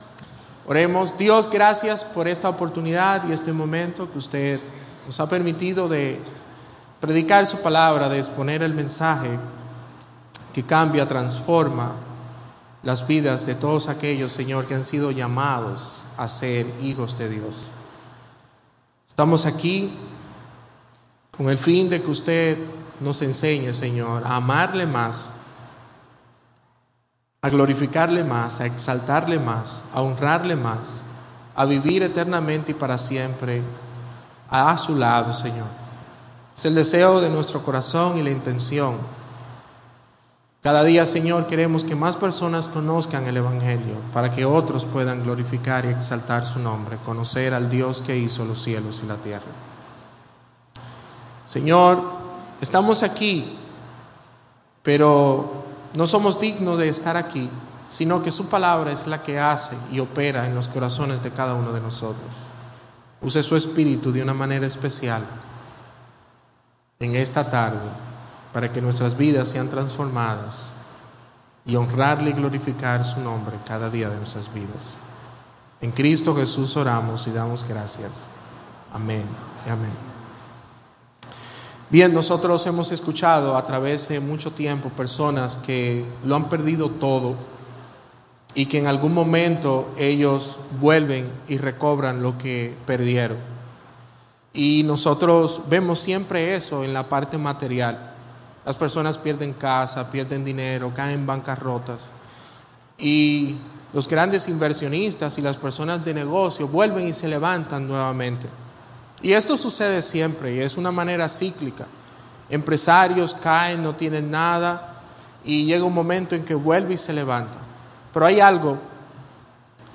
Predicado en el culto dominical vespertino y confraternidad de las iglesias bautistas de las provincias Hato Mayor y El Seibo.